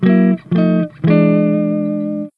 flagcapture_opponent.ogg